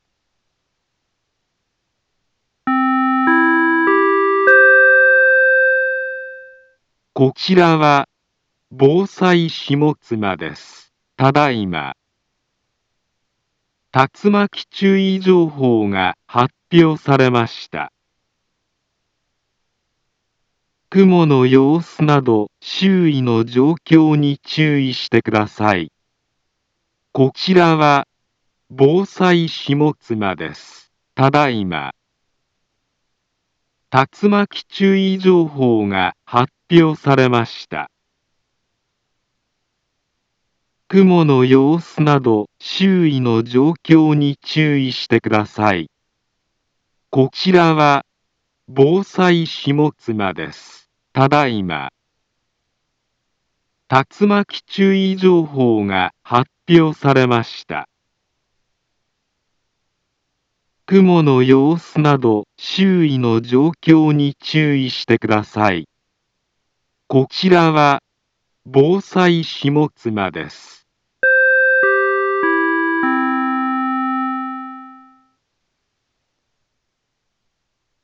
Back Home Ｊアラート情報 音声放送 再生 災害情報 カテゴリ：J-ALERT 登録日時：2023-07-12 16:35:09 インフォメーション：茨城県北部、南部は、竜巻などの激しい突風が発生しやすい気象状況になっています。